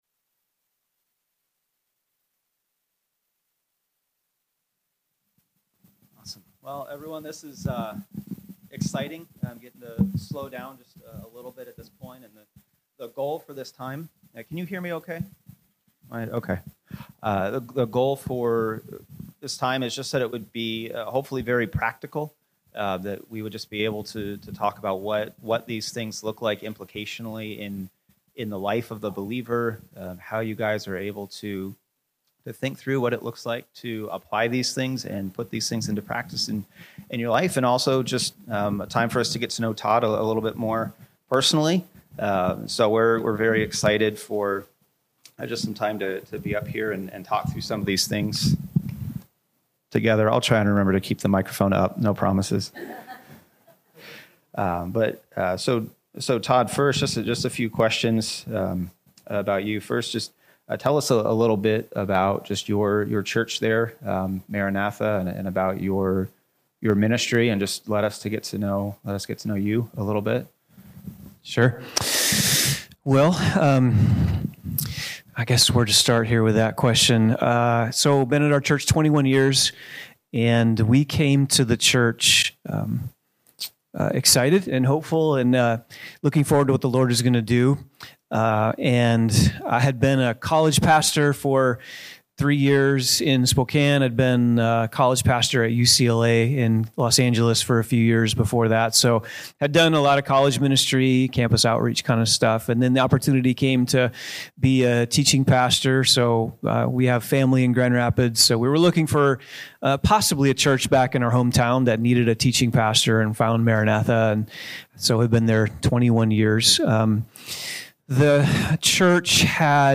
From Series: "2025 Church Conference"